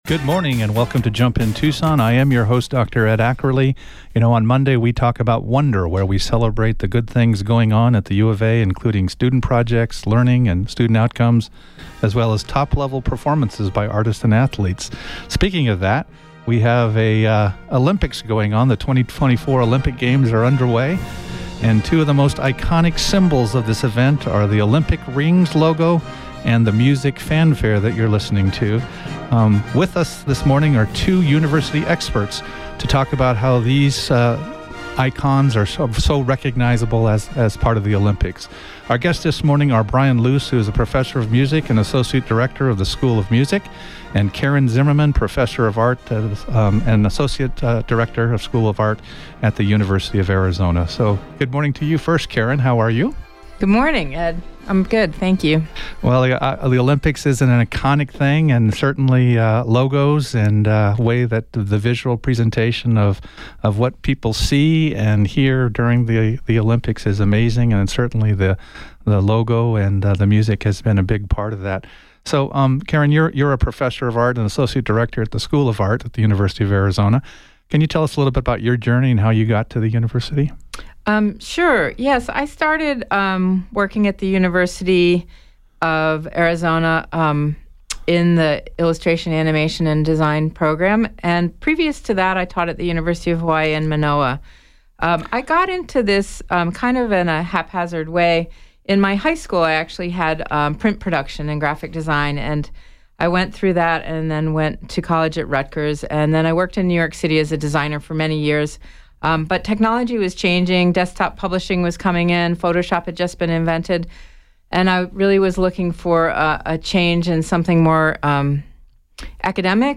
Based on the 8/5/24 Jump In Tucson on KVOI-1030AM in Tucson, AZ.